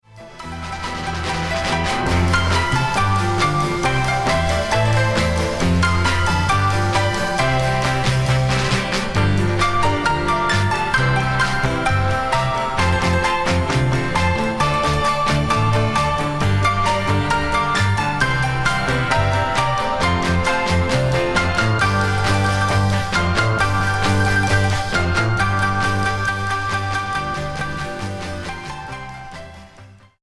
Pianist
performed in Honky-Tonk style